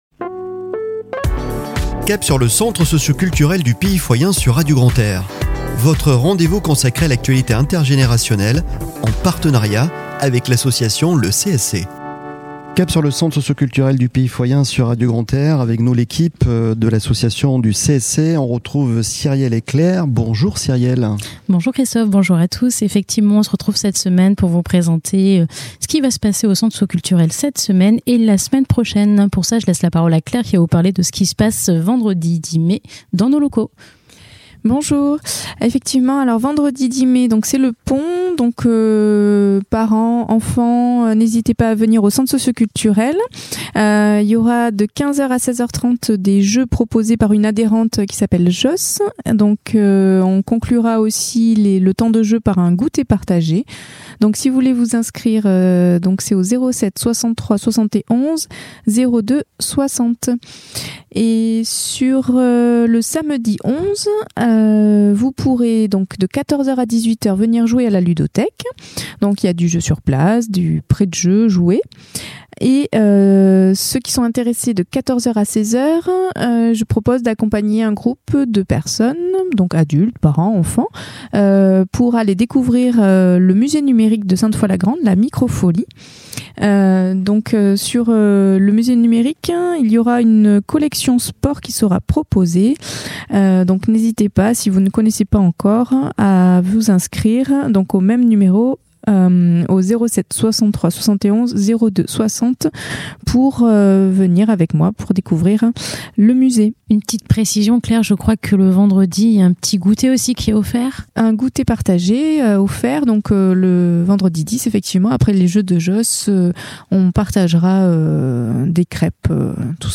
Chronique de la semaine du 06 au 11 Mai 2024 !